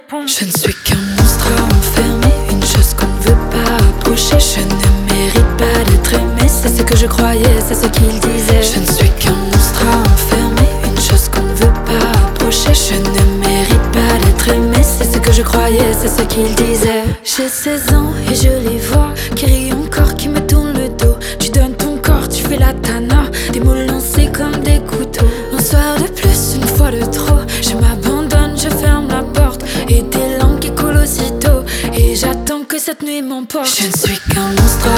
French Pop
2025-03-21 Жанр: Поп музыка Длительность